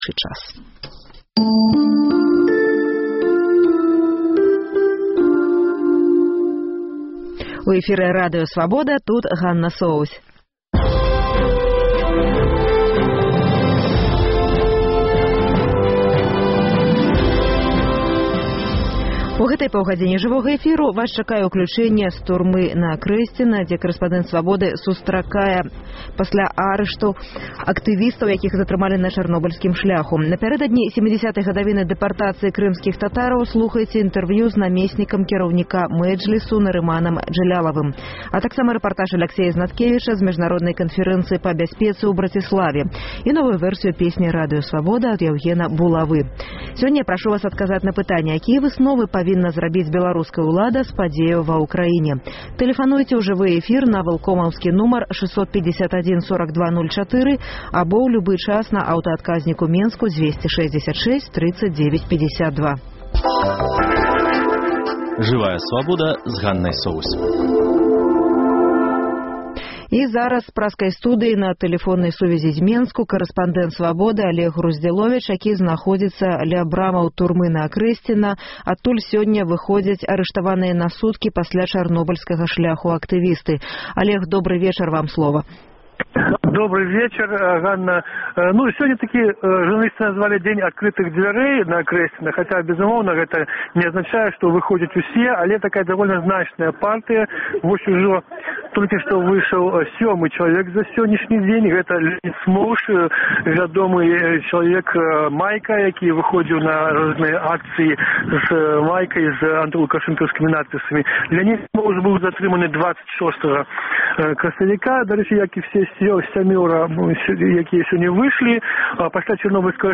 Сёньня ў праграме: З Акрэсьціна вызваляюць арыштаваных падчас Чарнобыльскага шляху. Жывое ўключэньне Ці гатовы Эўразьвяз увесьці эканамічныя санкцыі супраць Расеі?